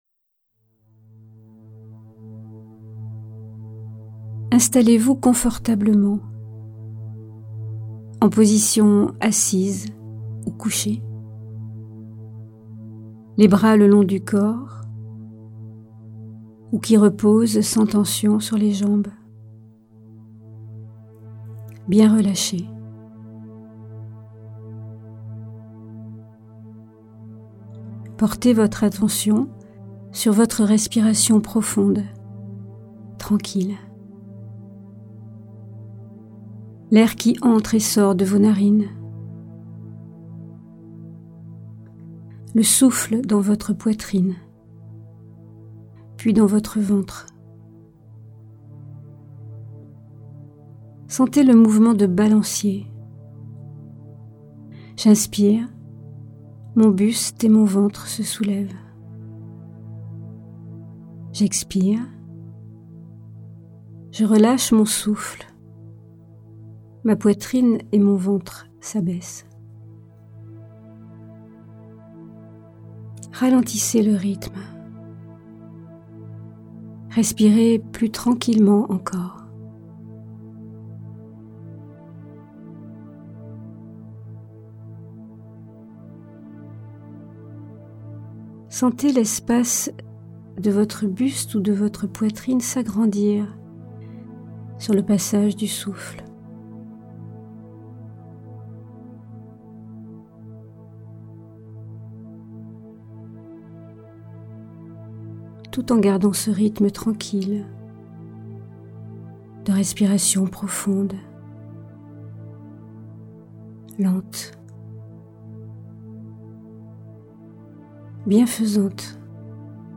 Méditations guidées pour trouver le sommeil mp3
Diffusion distribution ebook et livre audio - Catalogue livres numériques